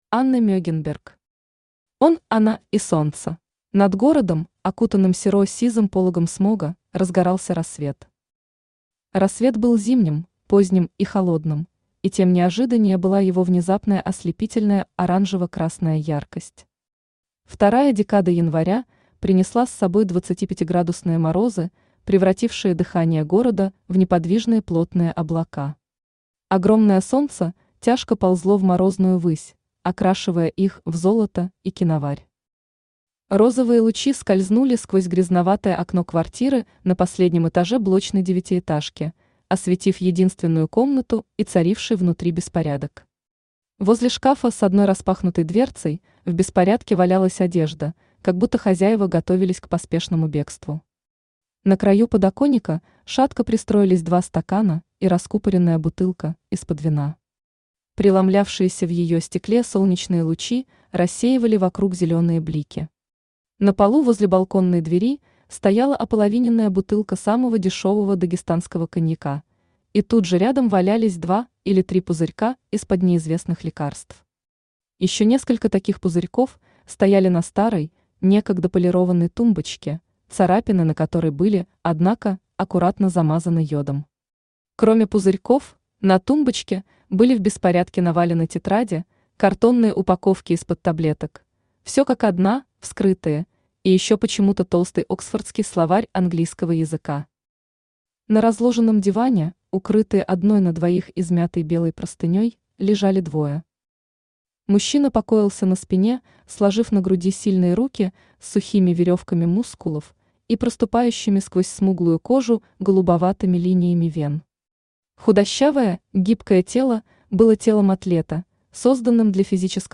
Aудиокнига Он, она и солнце Автор Анна Мегенберг Читает аудиокнигу Авточтец ЛитРес.